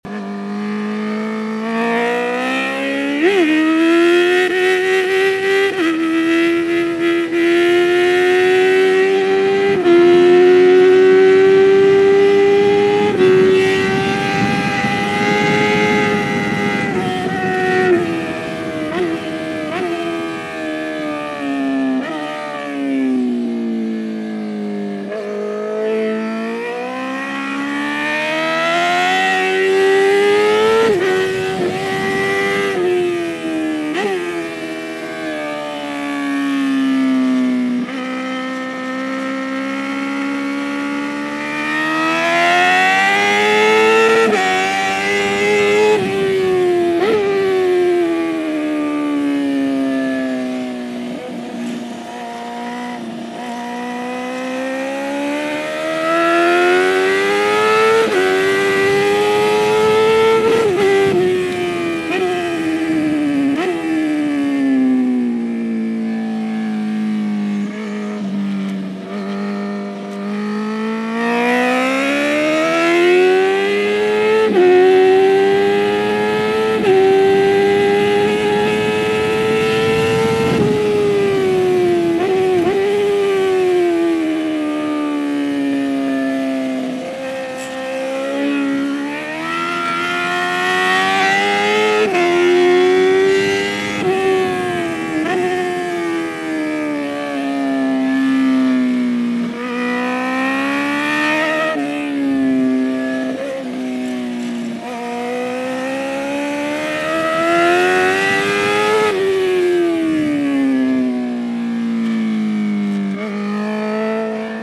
oreilles avec de délicieux chants de moteurs et d'échappements qui nous font si souvent
l'agonie du slider.mp3